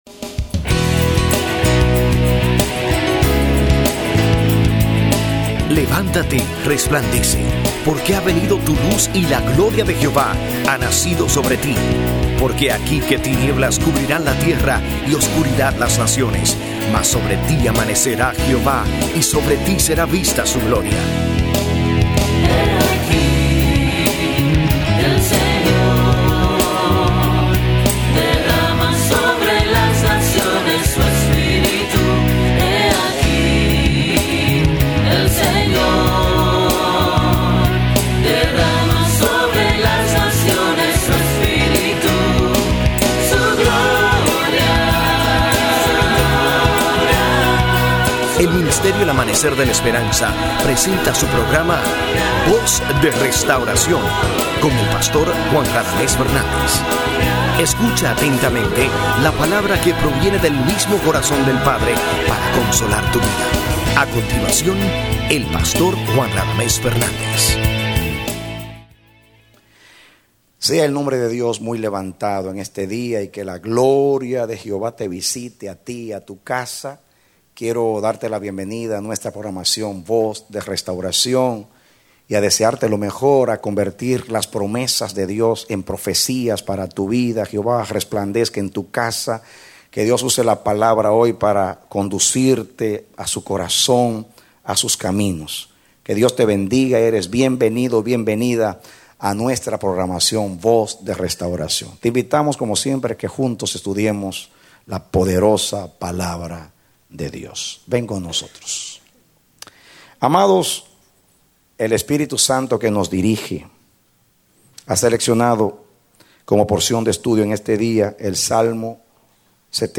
A mensaje from the serie "El Arco Engañoso." Parte B Serie de 2 Predicado Septiembre 5, 2010